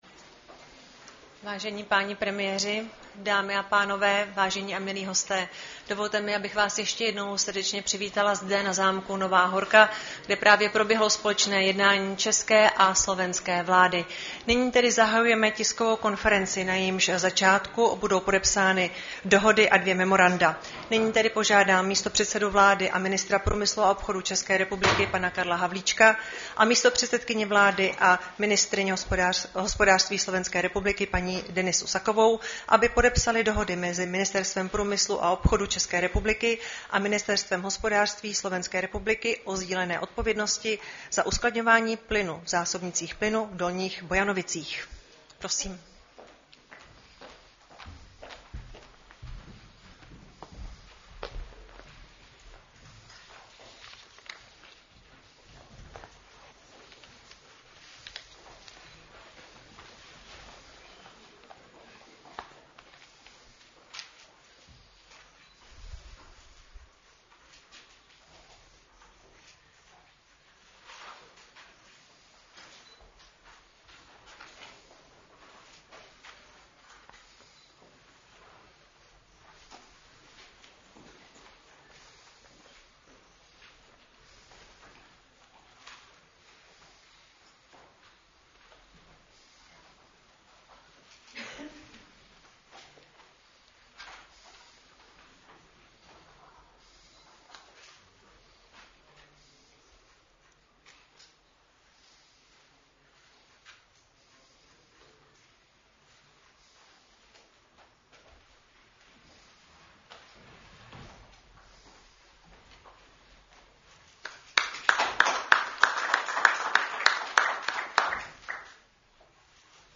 Tisková konference po společném zasedání české a slovenské vlády, 31. března 2026